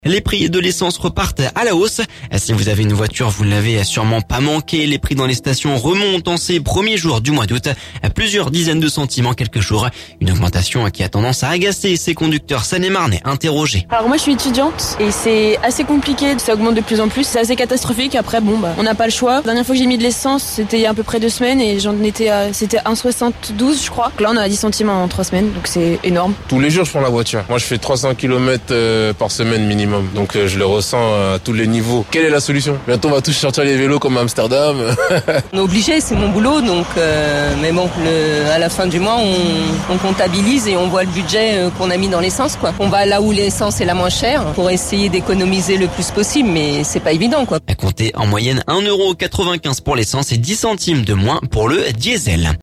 Une augmentation qui agace ces conducteurs Seine-et-Marnais interrogés…